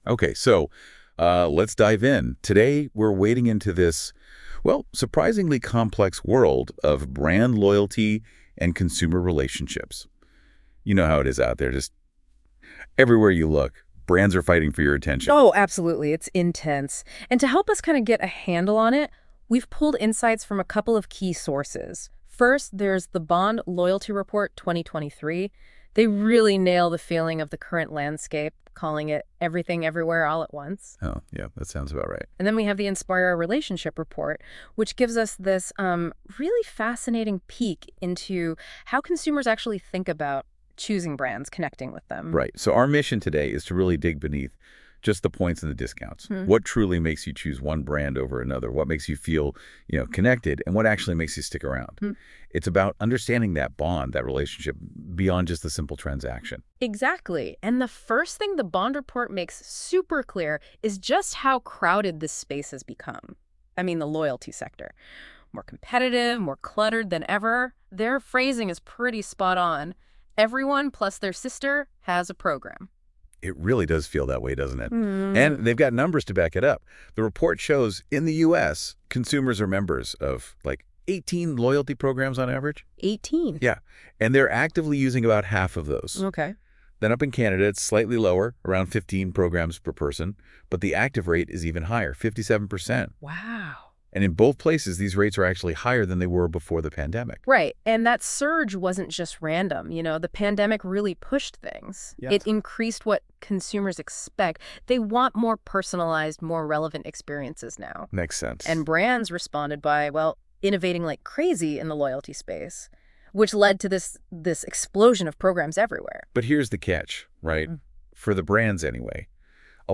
And yes, maybe we used a little AI magic to help us pull it off, but in the spirit of transparency (you’ll learn consumers say transparency builds trust!) we wanted to be open and honest.